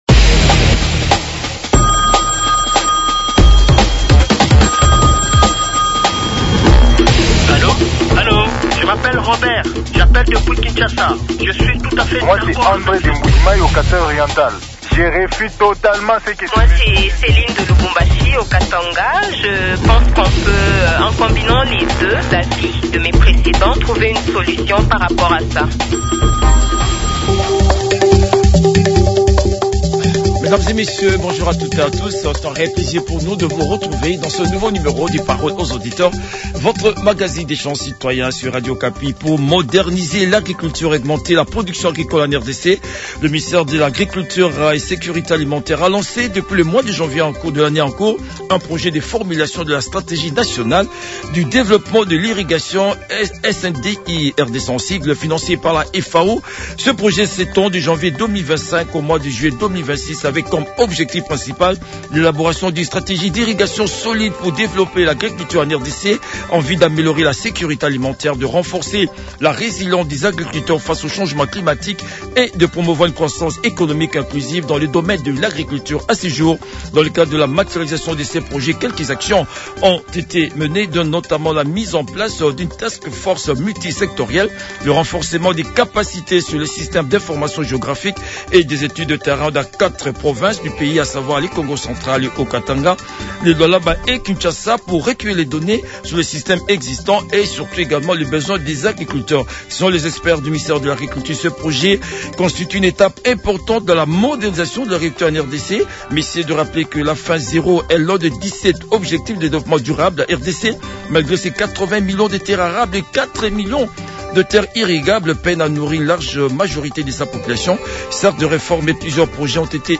les auditeurs ont débattu